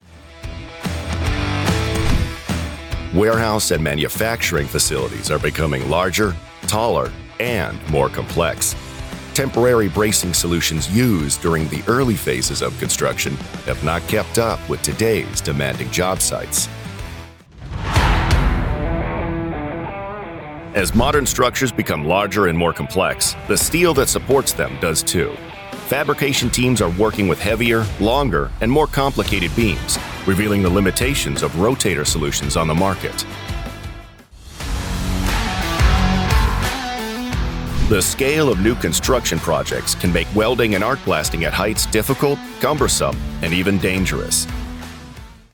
Never any Artificial Voices used, unlike other sites.
Male
Adult (30-50)
Deep Powerful Voice Demo
All our voice actors have professional broadcast quality recording studios.
0515DEMO_deep_powerful.mp3